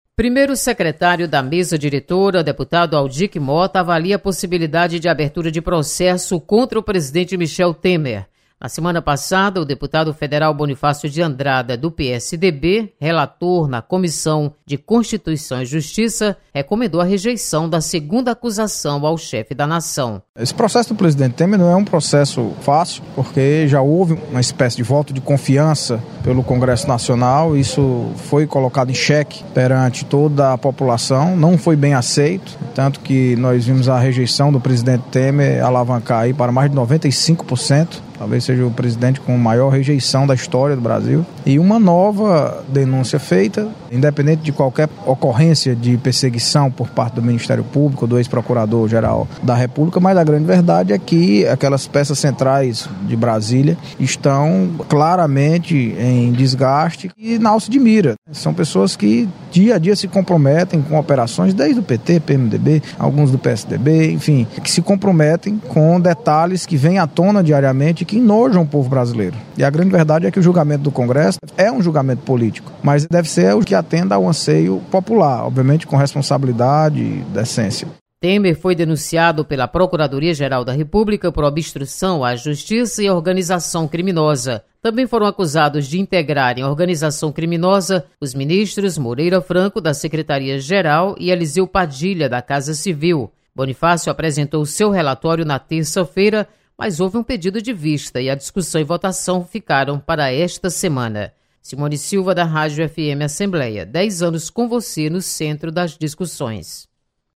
Audic Mota comenta processo contra presidente Michel Temer. Repórter